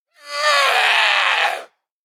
DayZ-Epoch/SQF/dayz_sfx/zombie/chase_13.ogg at 60177acd64446dce499ec36bbd9ae59cdc497fff
chase_13.ogg